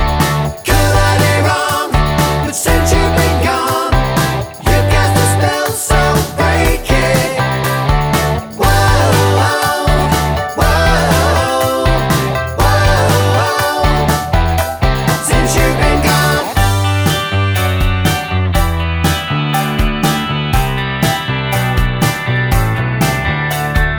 Three Semitones Down Rock 3:23 Buy £1.50